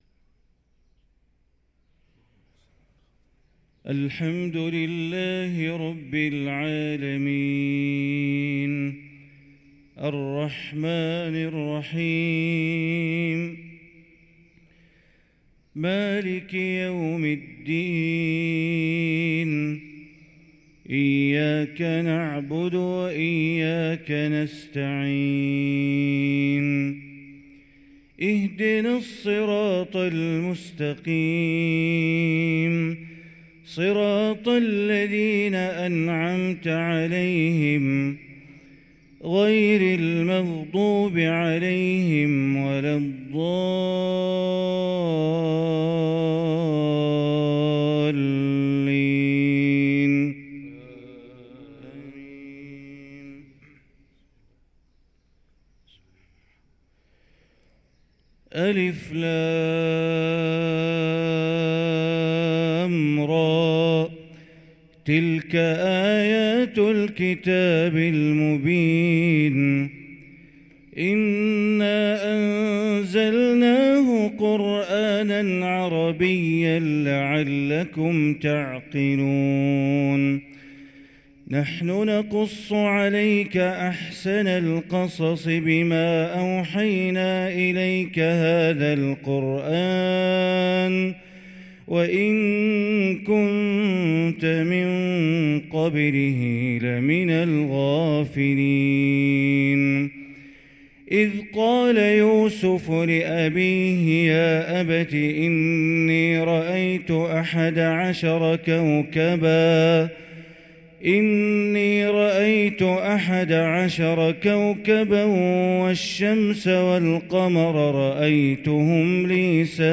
صلاة الفجر للقارئ بندر بليلة 7 ربيع الآخر 1445 هـ
تِلَاوَات الْحَرَمَيْن .